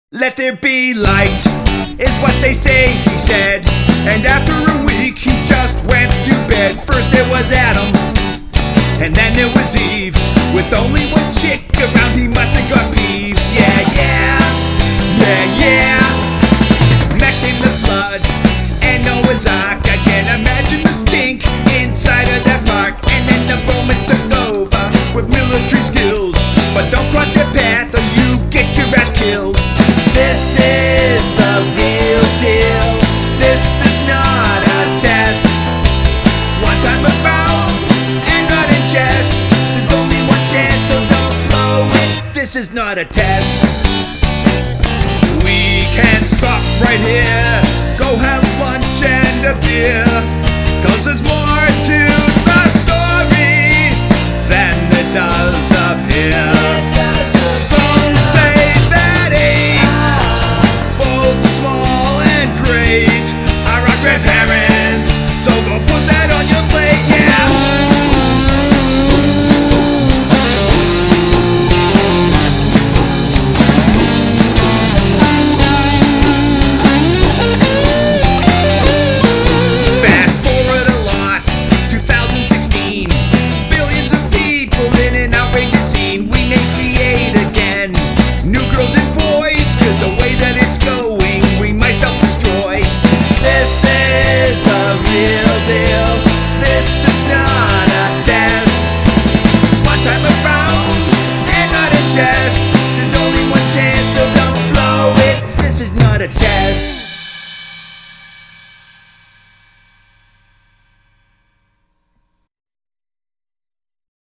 Also, cool guitar solo and everything.